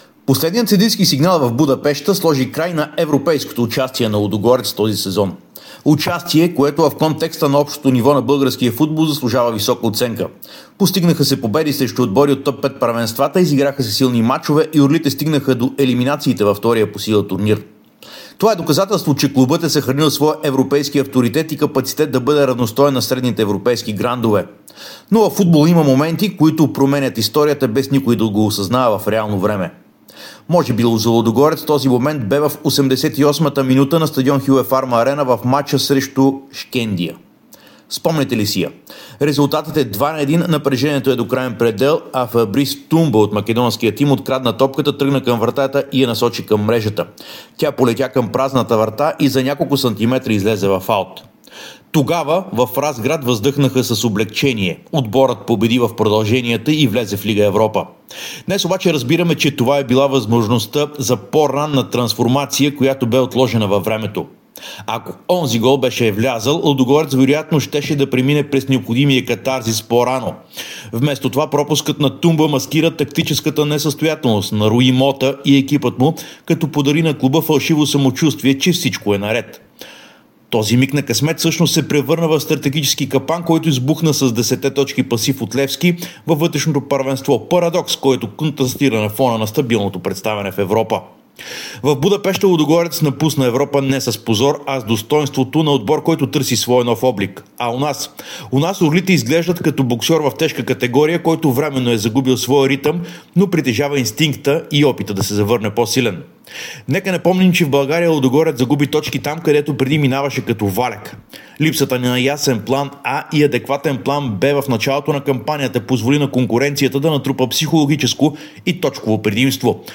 Коментар